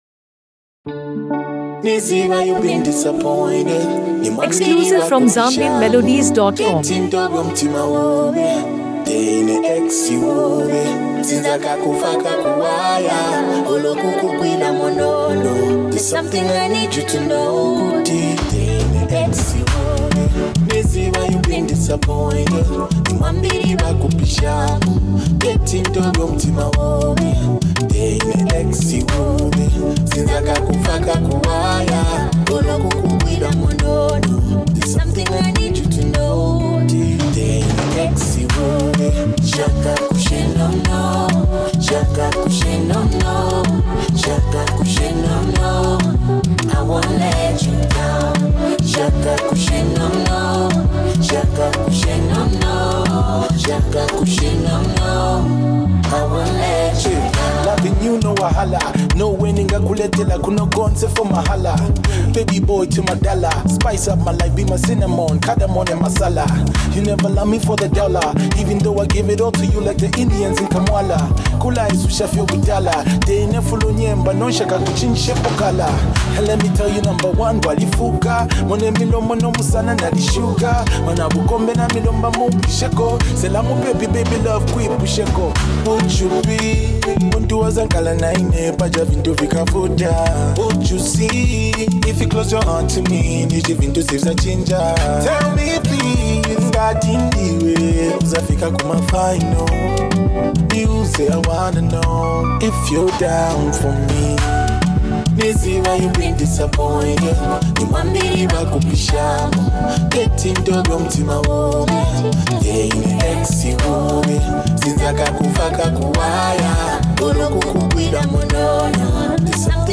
Genre: Afro-Pop/RnB